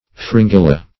Fringilla \Frin*gil"la\, a. [NL., fr. L. fringilla a chaffinch.]